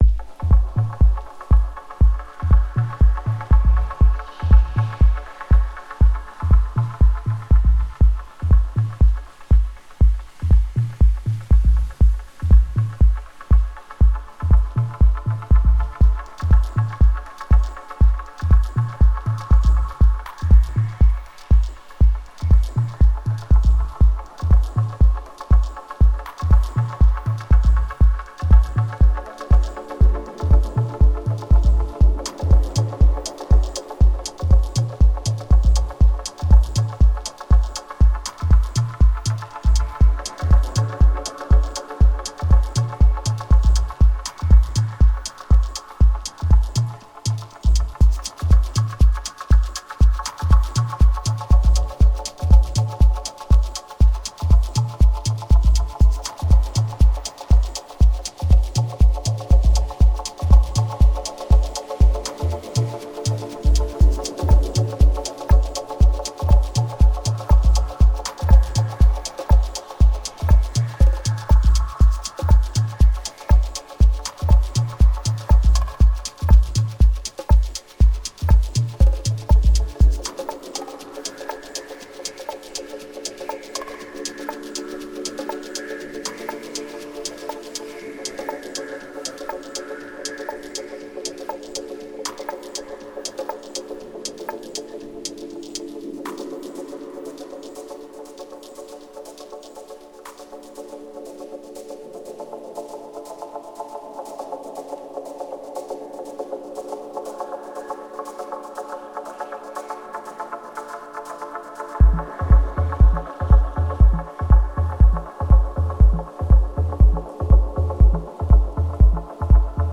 Best synth for dub chords (dub techno)
Again with some more mellow dubsounds.
Ran the Digitone through my Quandrantid Swarm on this one.
The dual analogue filters and the spring reverb is great for these sounds.
Digitakt doing everything else.